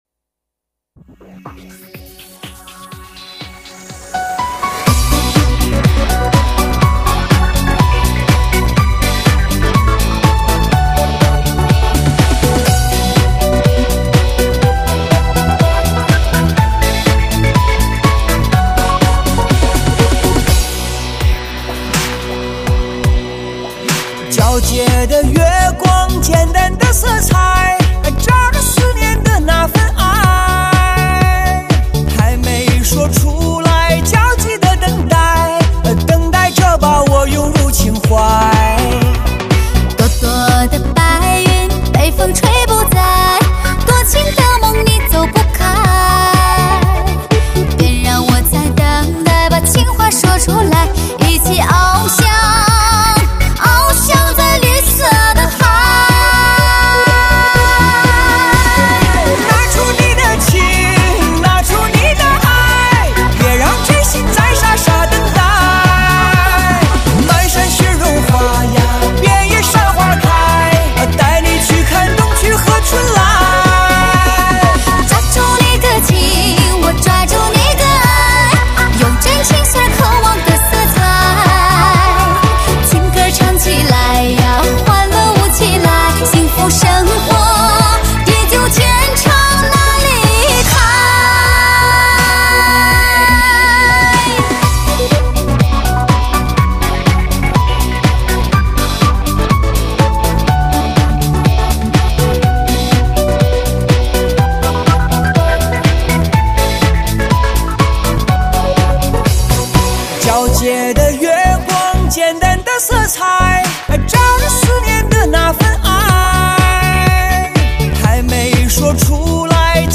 颠覆传统，摇滚嗨翻喜歌
融入disco元素，爱可以忘我，疯狂。